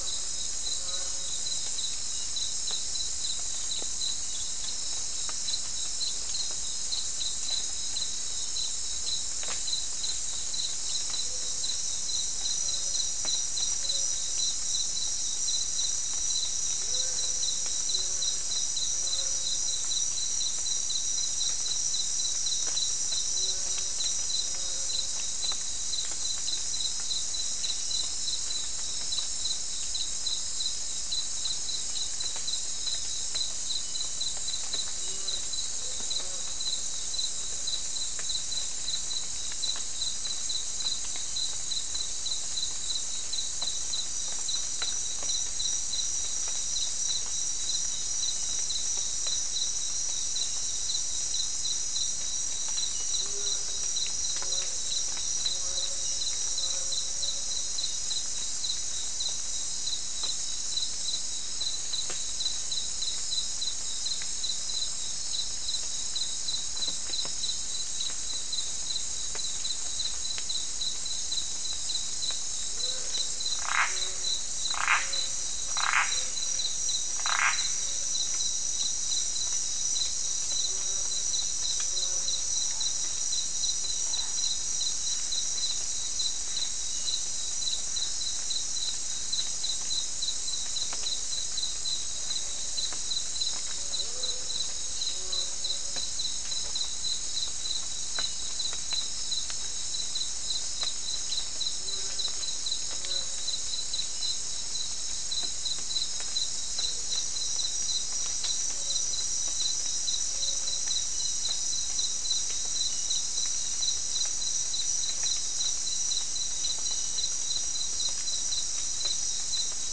Non-specimen recording: Soundscape Recording Location: South America: Guyana: Mill Site: 3
Recorder: SM3